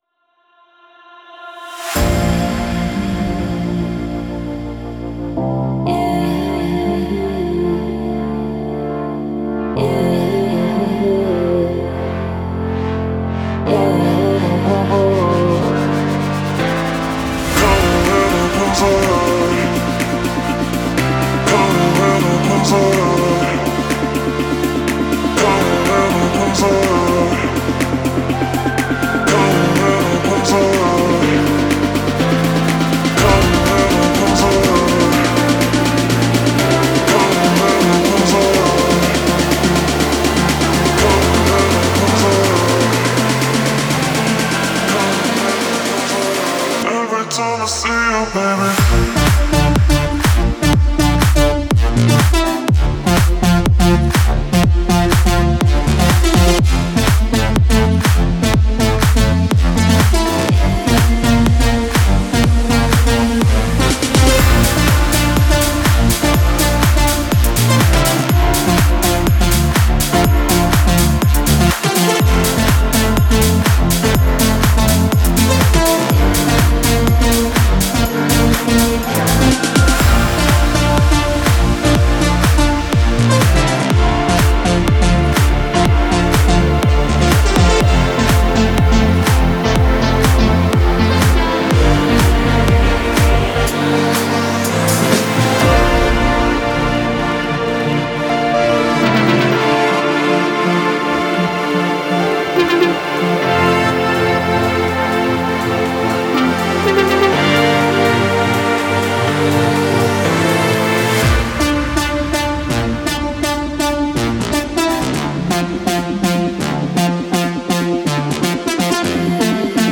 это энергичная танцевальная композиция в жанре EDM